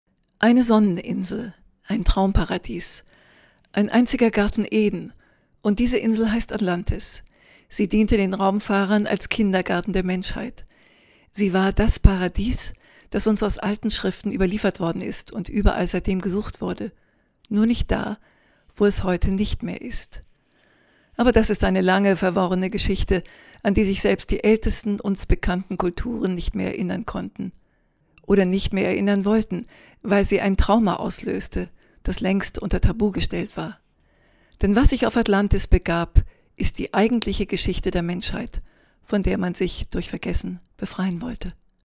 professionelle deutsche Sprecherin.
Sprechprobe: Sonstiges (Muttersprache):
german female voice over artist